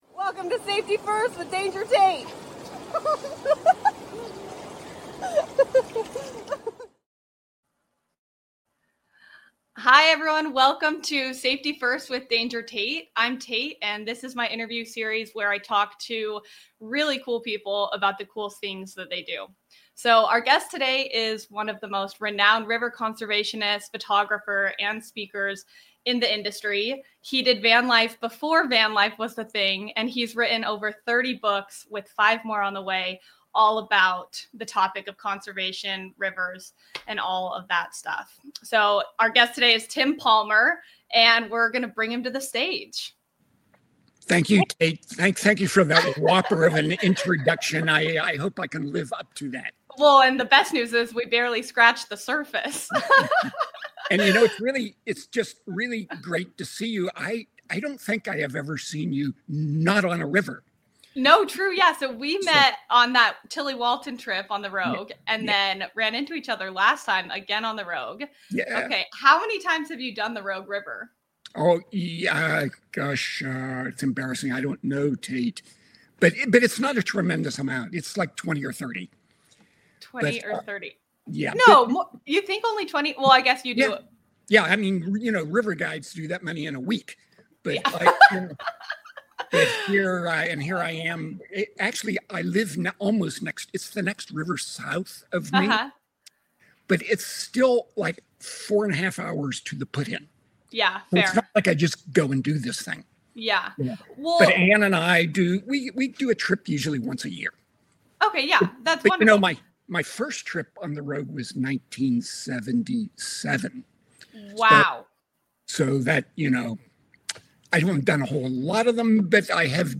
each episode features compelling conversations with industry leaders, adventurers, and experts who share their stories, insights, and actionable tips for navigating the wild and protecting our planet.